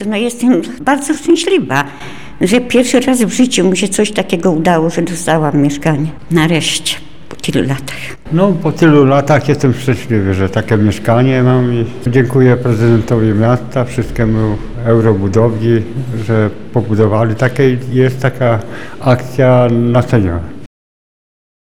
Mieszkańcy nie kryją dziś szczęścia.